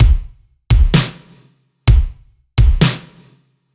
Index of /musicradar/french-house-chillout-samples/128bpm/Beats
FHC_BeatC_128-01_KickSnare.wav